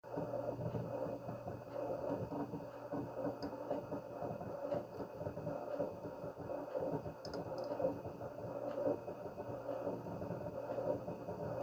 Hat noch jemand, dass das Nas klingt wie ein alter Schiffsdiesel?
Nur das Geräusch ist irgendwie schräg Anhänge Sprache 001_sd.m4a Sprache 001_sd.m4a 216,3 KB